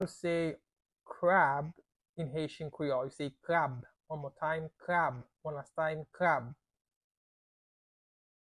Listen to and watch “Krab” audio pronunciation in Haitian Creole by a native Haitian  in the video below:
How-to-say-Crab-in-Haitian-Creole-Krab-pronunciation-by-a-Haitian-teacher.mp3